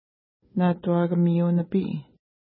Pronunciation: na:twa:kəmi:u-nəpi:
Pronunciation